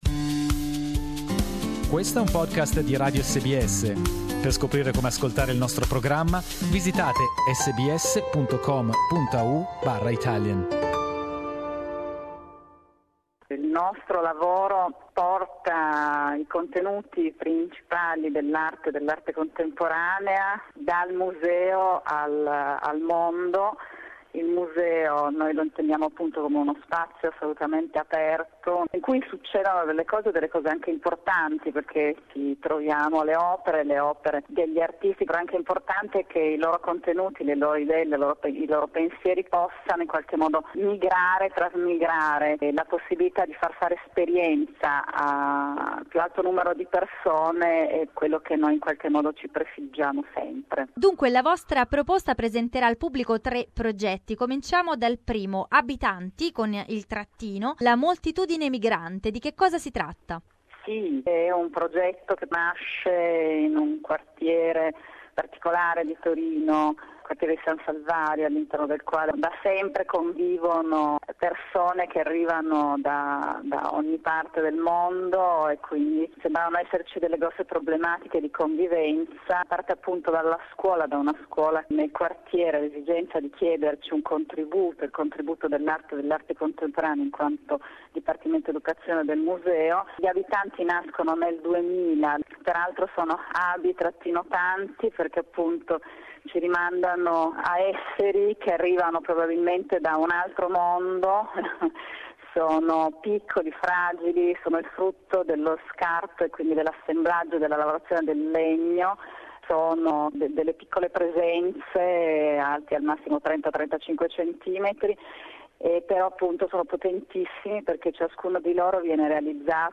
It is the slogan of the Melbourne's Arts Learning Festival. in this interview